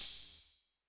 Open Hats
open_hat 1 2 3.wav